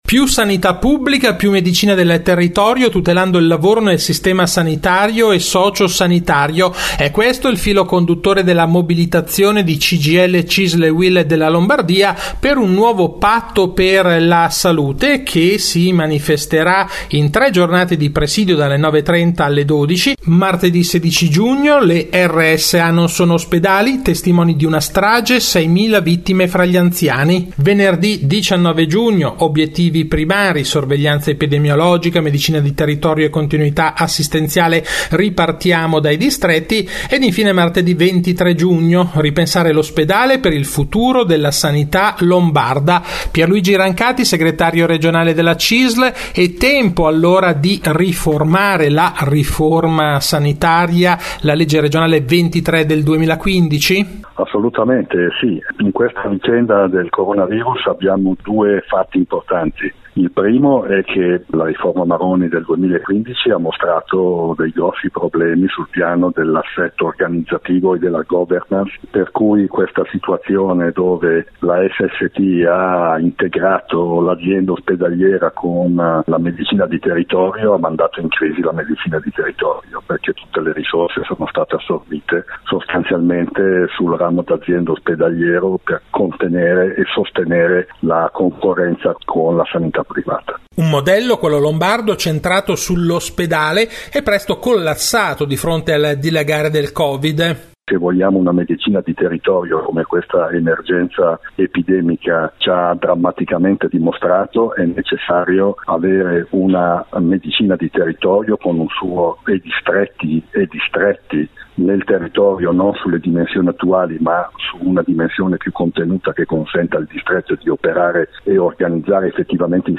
Di seguito la puntata dell’11 giugno di RadioLavoro, la rubrica d’informazione realizzata in collaborazione con l’ufficio stampa della Cisl Lombardia e in onda tutti i giovedì alle 18.20 su Radio Marconi in replica il venerdì alle 12.20.
Questa settimana intervista